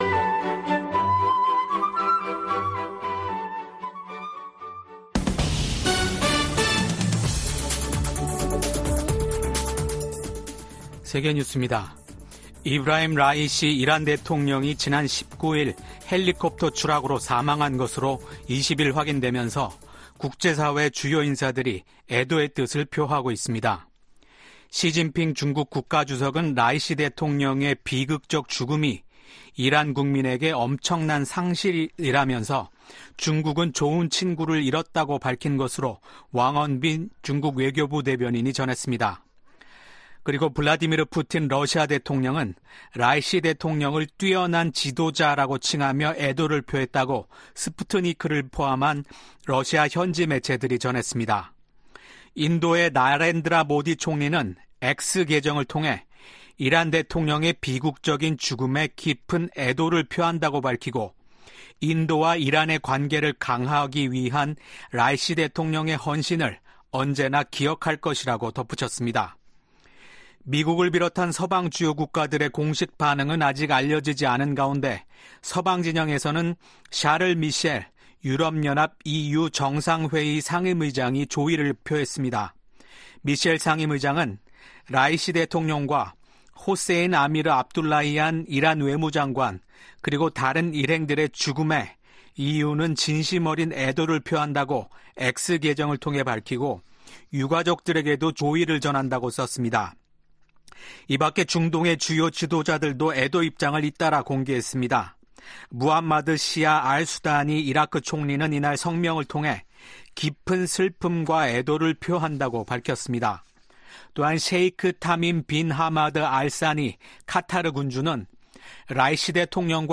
VOA 한국어 아침 뉴스 프로그램 '워싱턴 뉴스 광장' 2024년 5월 21일 방송입니다. 미국 국무부가 북한의 단거리 탄도미사일 발사를 규탄하며 거듭되는 북한 미사일 발사의 불법성을 지적했습니다. 북한과 러시아가 대량살상무기 관련 불법 금융활동 분야에서 가장 큰 위협국이라고 미국 재무부가 밝혔습니다. 미국, 한국, 일본의 협력 강화를 독려하는 결의안이 미국 하원 외교위원회를 통과했습니다.